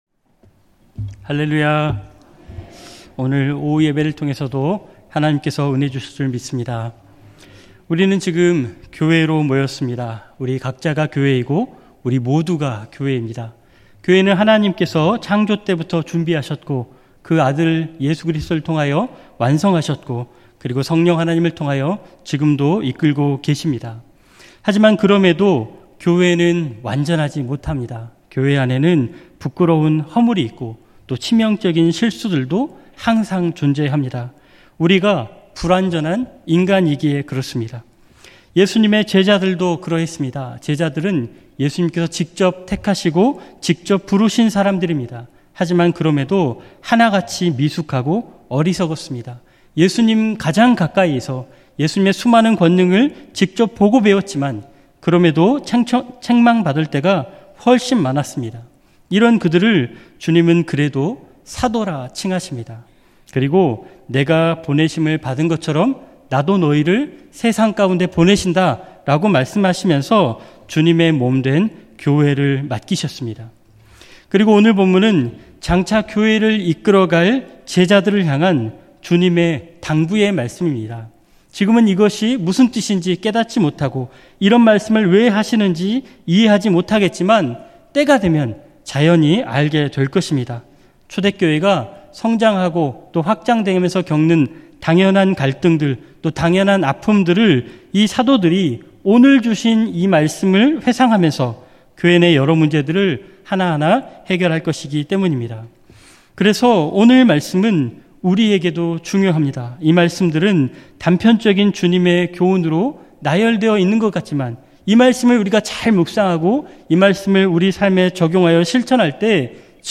2024년 7월 14일 주일오후예배
건강한 교회를 이루는 4가지 방법 음성설교 듣기 MP3 다운로드 목록 이전 다음